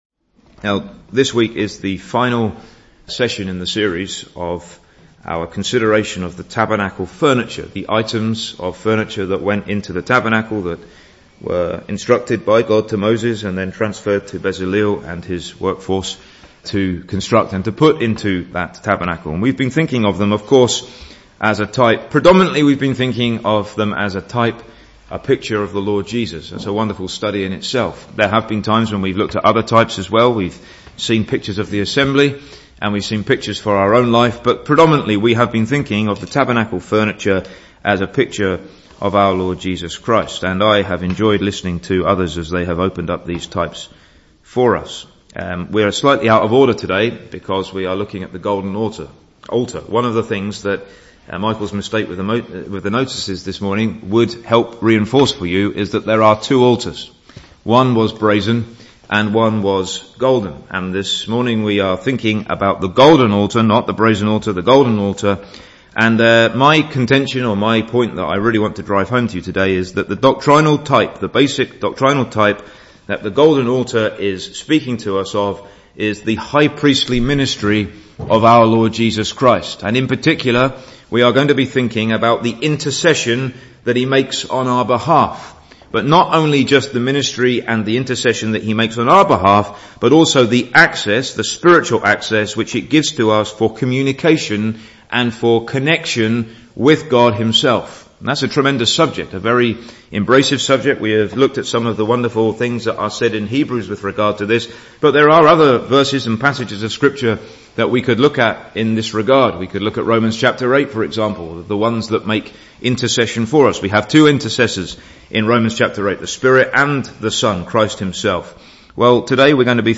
sympathising and supplicating work as our Great High Priest (Message preached Dec 11th 2016)